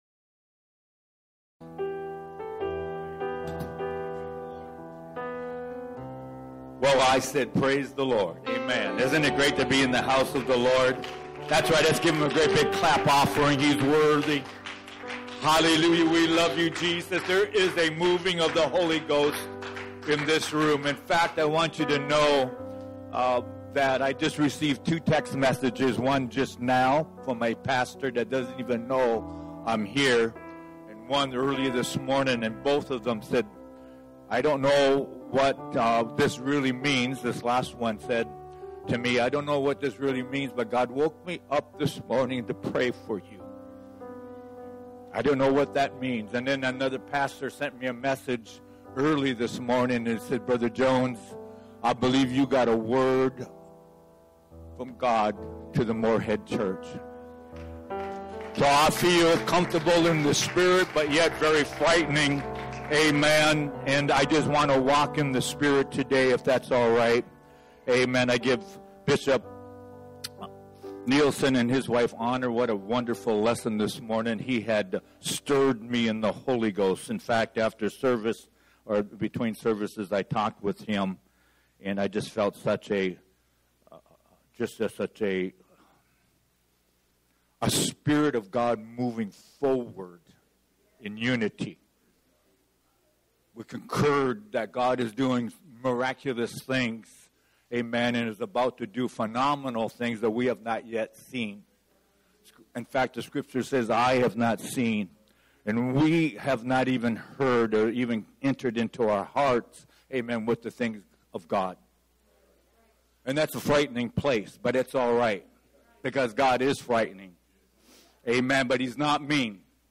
Sermons | LifePoint Sanctuary